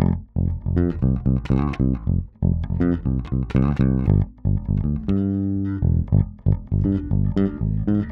28 Bass PT4.wav